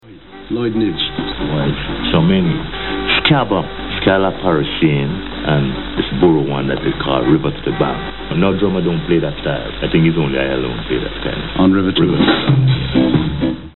Interview with the Skatalites by David Rodigan in 1984
In 1984 David Rodigan, the well known DJ from Capital Radio, interviewed the Skatalites after the concert at Crystal Palace in London, Reggae Sunsplash Festival.